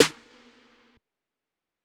Metro Snares [Open].wav